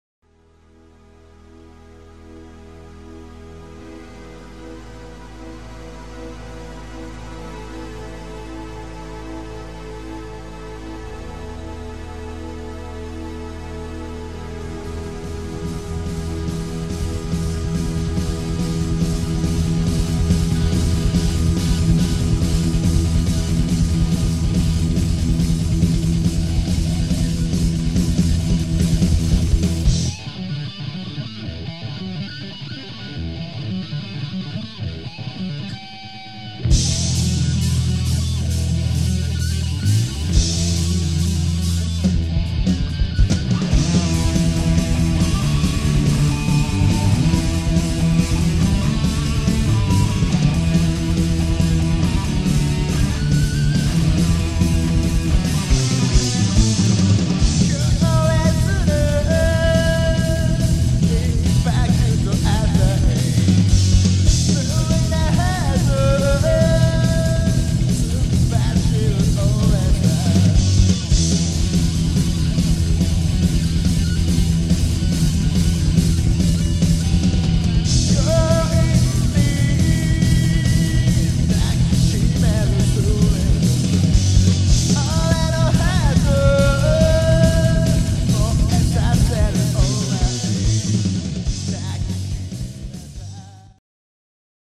ミディアムテンポでメロディアスなサウンドを中心とした正統派ハードロックバンドである。